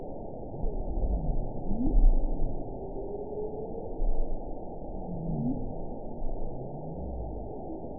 event 922095 date 12/26/24 time 10:37:37 GMT (11 months, 1 week ago) score 8.56 location TSS-AB10 detected by nrw target species NRW annotations +NRW Spectrogram: Frequency (kHz) vs. Time (s) audio not available .wav